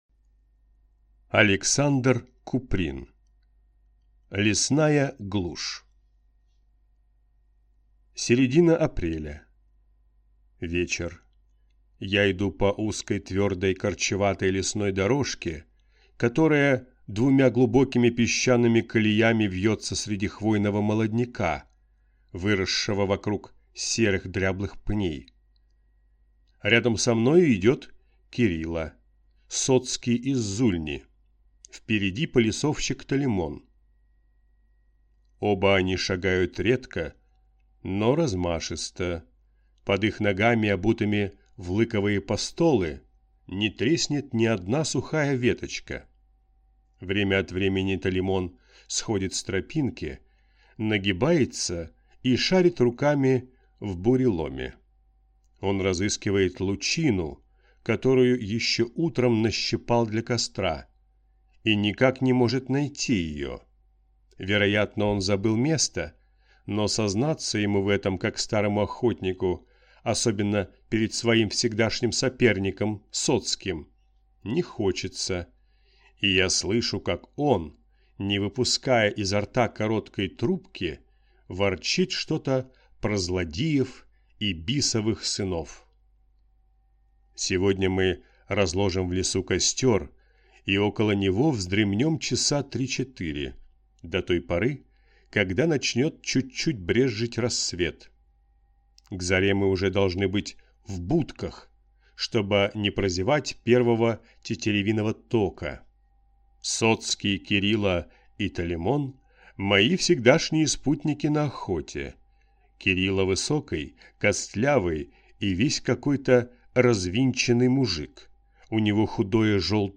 Аудиокнига Лесная глушь | Библиотека аудиокниг